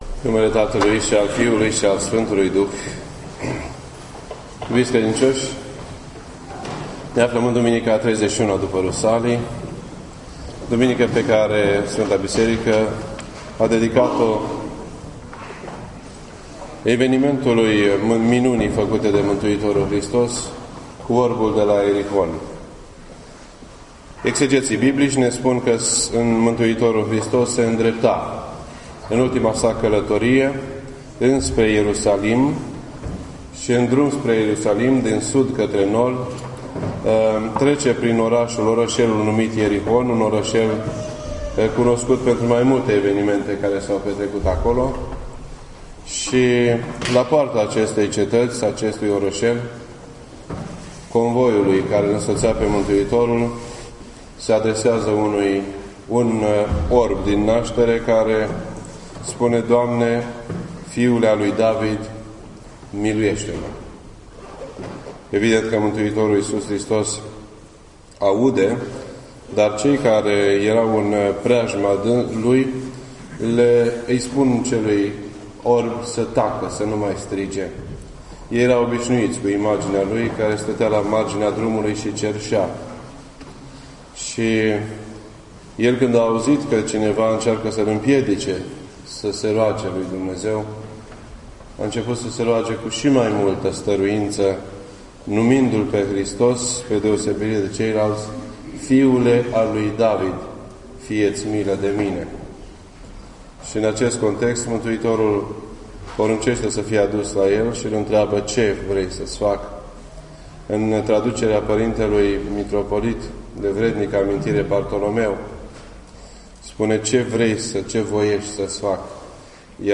This entry was posted on Sunday, January 27th, 2013 at 8:39 PM and is filed under Predici ortodoxe in format audio.